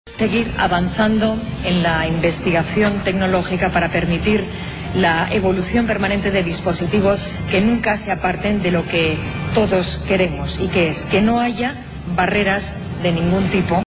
La reina destacó la importancia de la investigación tecnológica